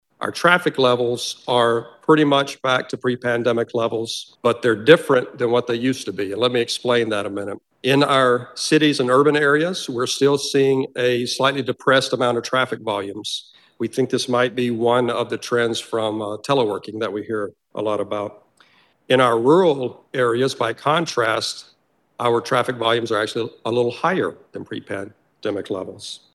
That’s Iowa DOT director Scott Marler, who says railroad traffic nationwide increased seven percent in 2021, but has not rebounded to pre-pandemic levels.
Marler made his comments during a briefing for the Iowa House Transportation Committee.